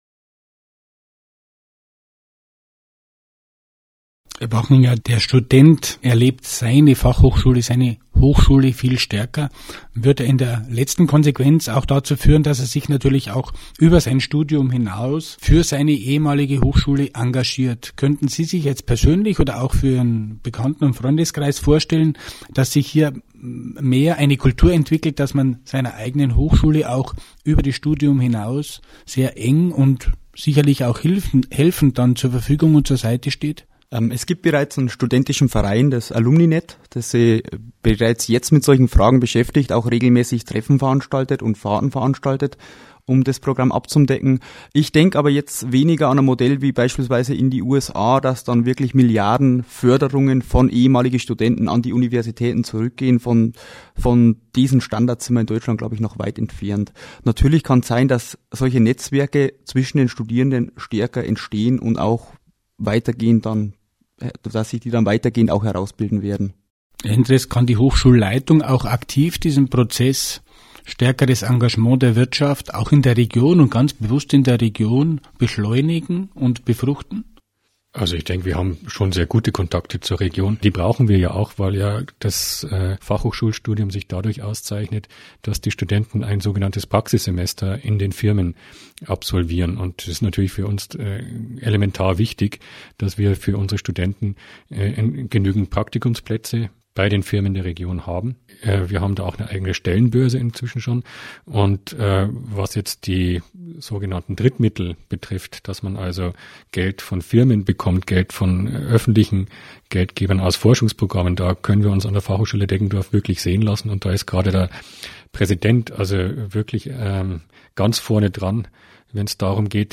Diskussionsrunde: Studienbeiträge
4. Beitrag: Diskussionsrunde Studiengebühren